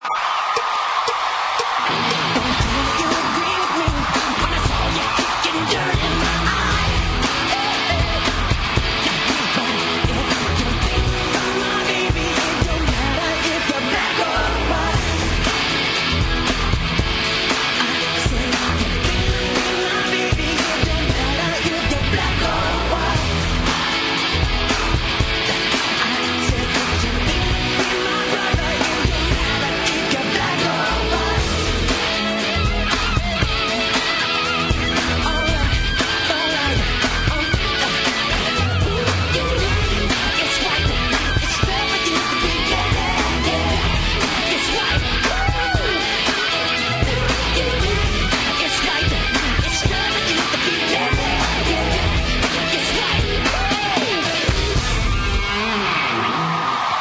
sur scène à New York en 2001